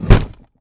barricade_destroy.wav